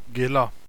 Lustenauerisch–Deutsch
gella :: weinen